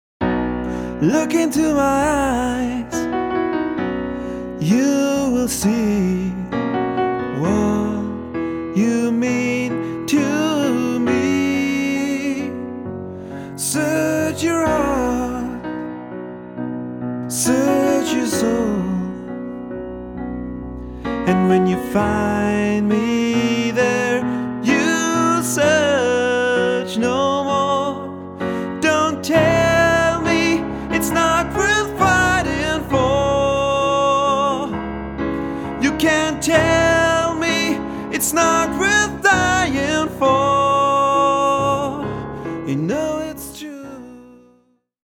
Duo oder Trio